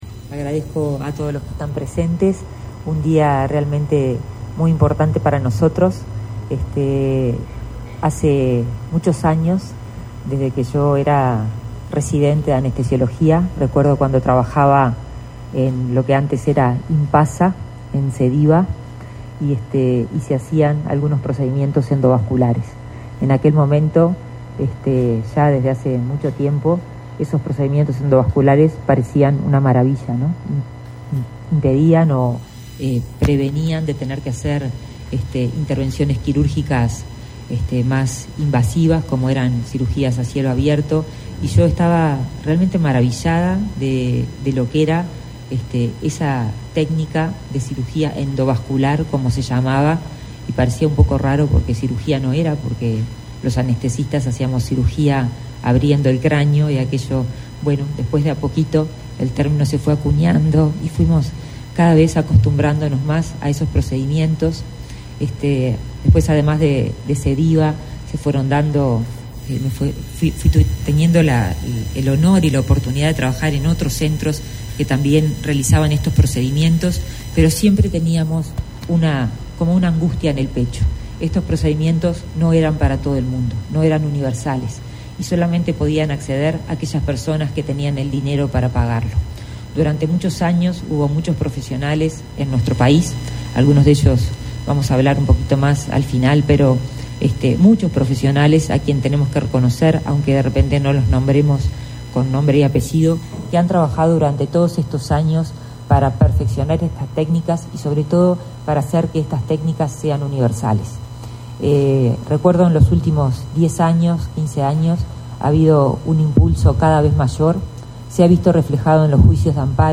Palabras de la ministra de Salud Pública, Karina Rando, y de la directora del Fondo Nacional de Recursos, María Ana Porcelli